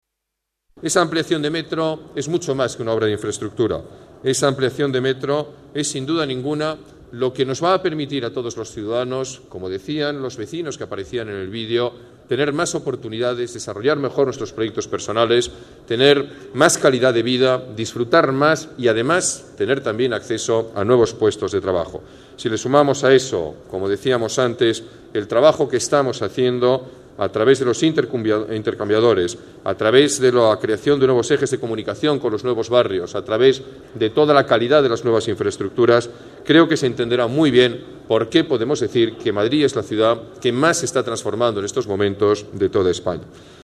GALLARDONINAGURACIONARGAPLANETARIO-26-01.MP3